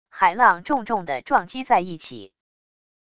海浪重重的撞击在一起wav音频